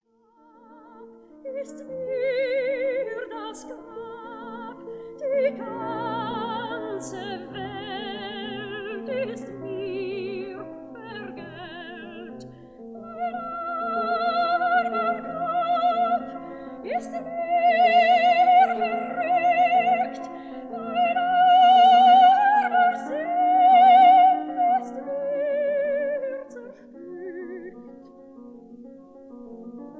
soprano
piano
Sofiensaal, Vienna